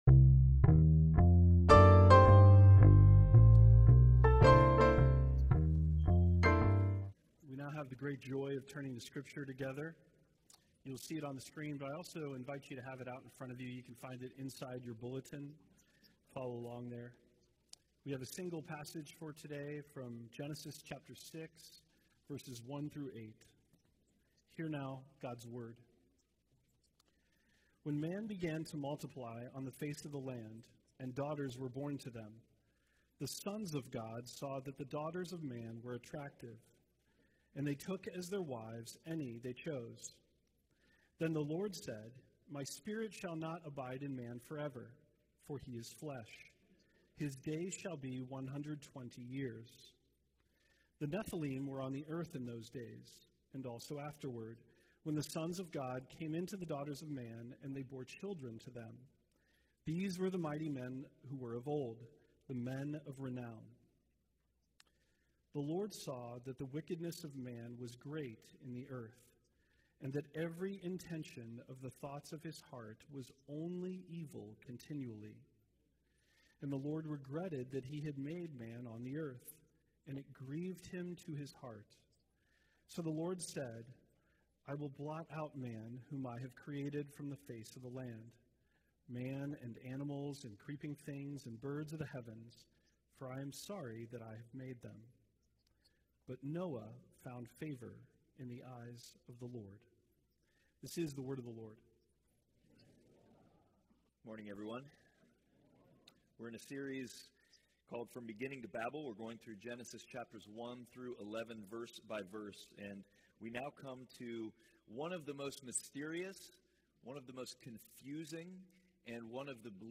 Passage: Genesis 6: 1-8 Service Type: Sunday Worship